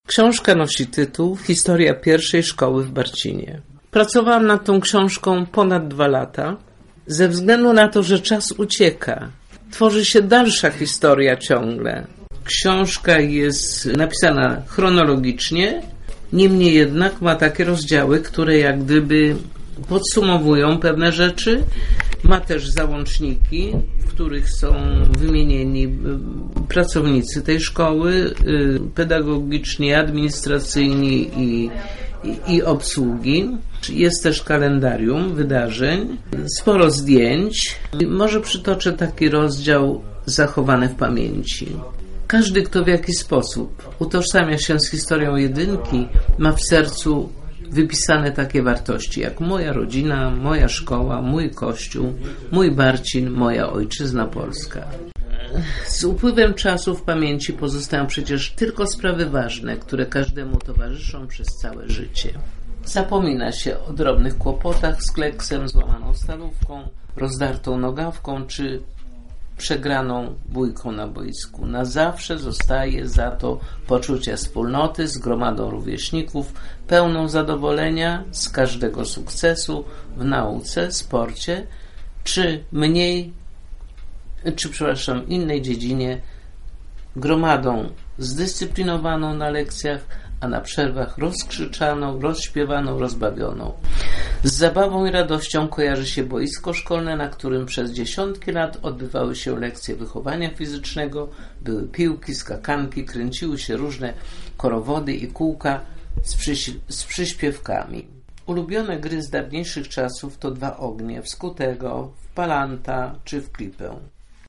30 grudnia w Barcinie odbyło się spotkanie zatytułowane "Wieczór wspomnień".
Relacja z gali "Wieczór Wspomnień" w Barcinie: